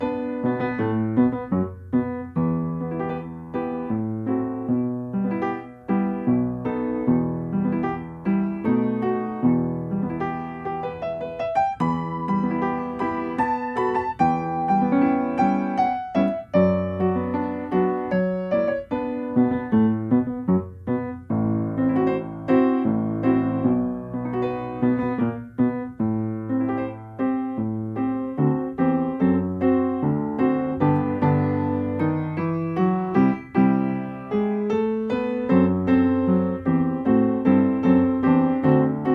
akompaniamentu pianina
Strój 440Hz
II wersja – ćwiczeniowa
Tempo: 76 bmp
Nagrane z metronomem.
Nagranie uwzględnia powtórkę.
Nagranie nie uwzględnia zwolnień.